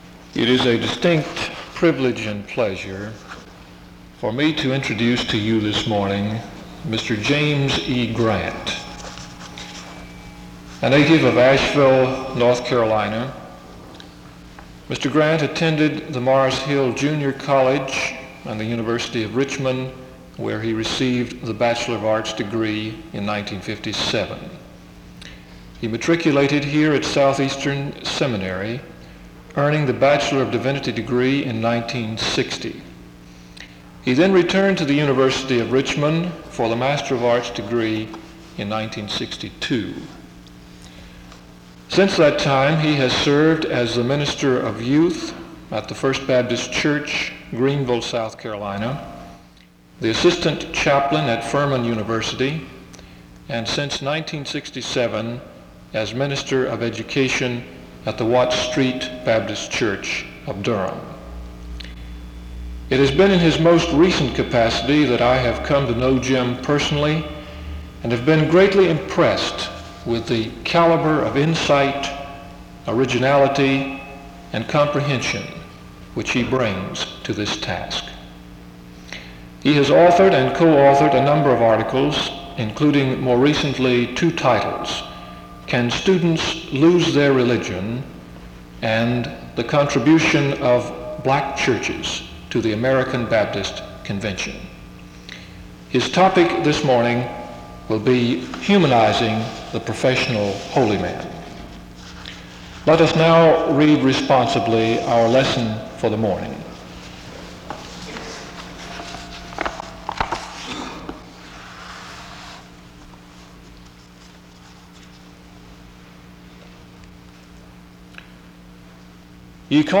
He closes in prayer (27:17-28:06). This chapel is distorted from 12:25-12:46, 17:10-17:23 and 22:40-23:05.